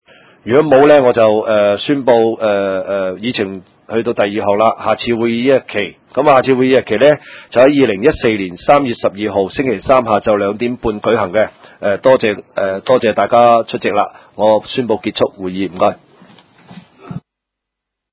委员会会议的录音记录
大埔区议会秘书处会议室